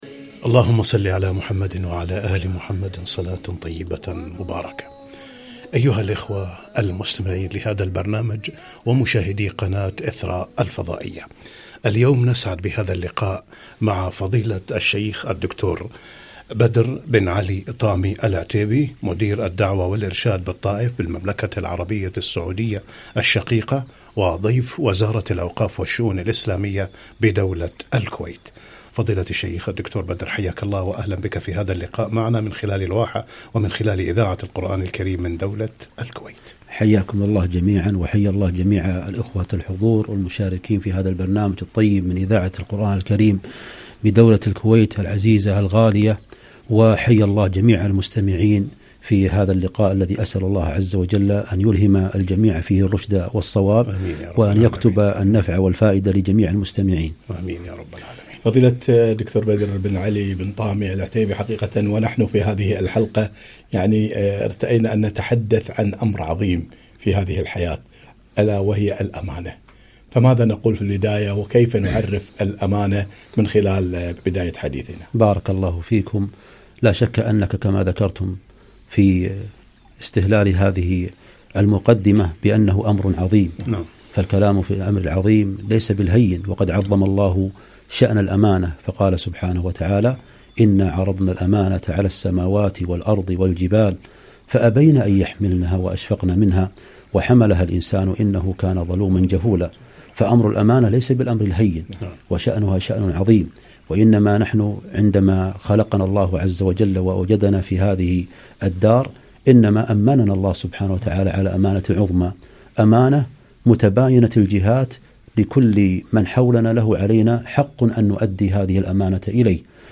لقاء إذاعي بعنوان الأمانة - إذاعة القرآن الكريم بدولة الكويت